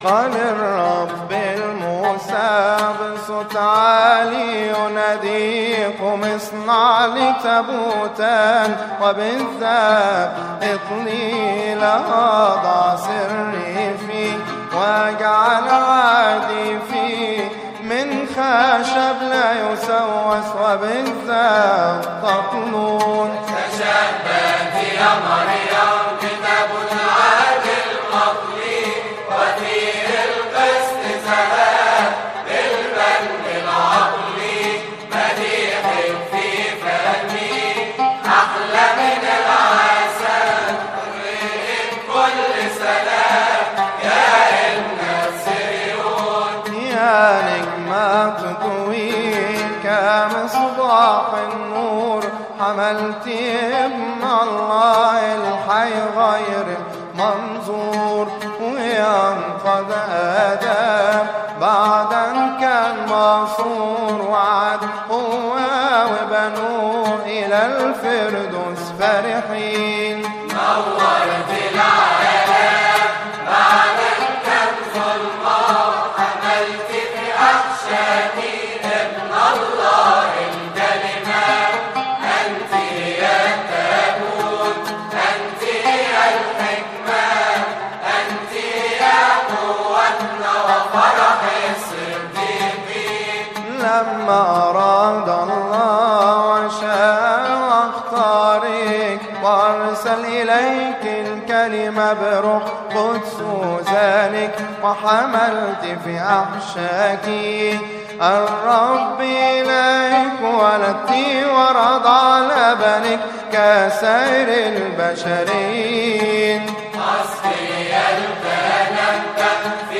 مكتبة الألحان